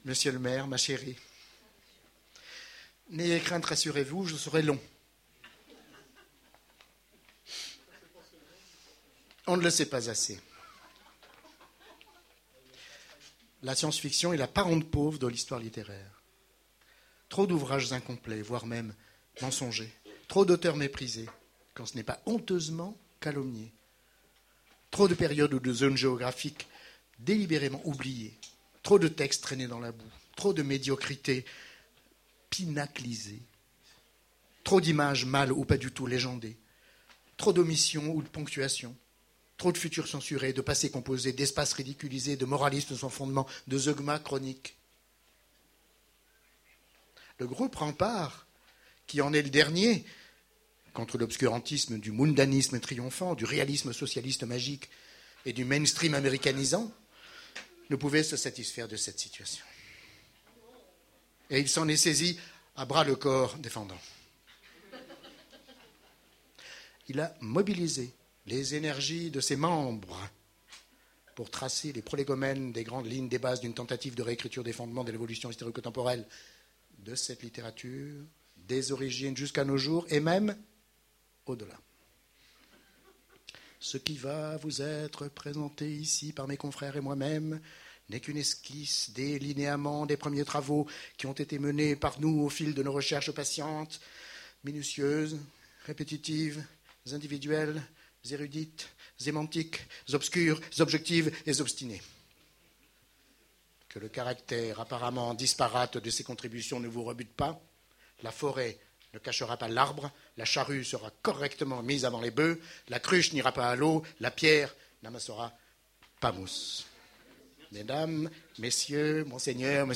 Voici l'enregistrement de la conférence du groupe Rempart à la convention 2010